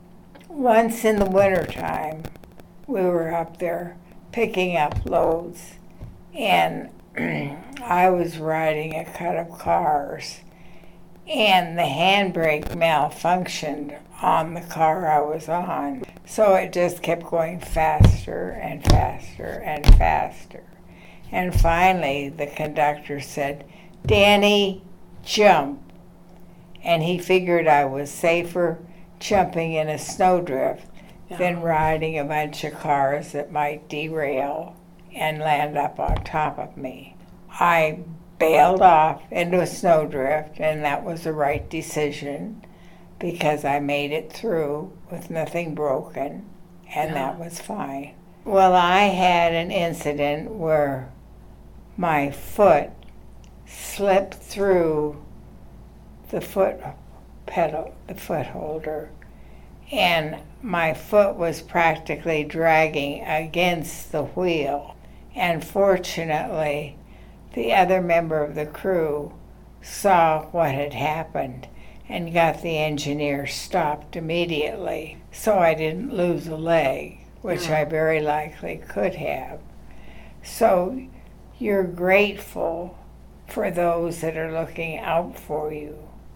“Life Between the Rails” oral history project, Coll.